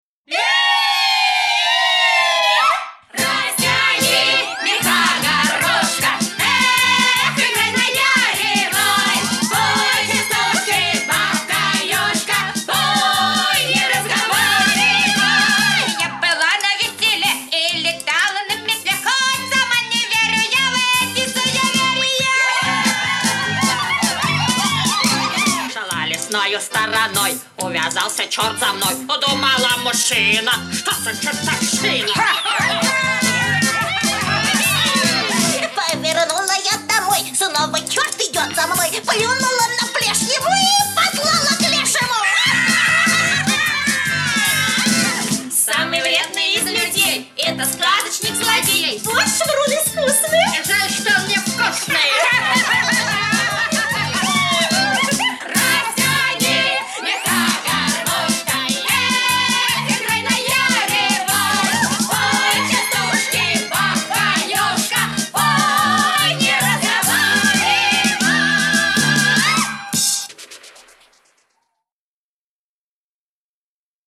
• Категория: Частушки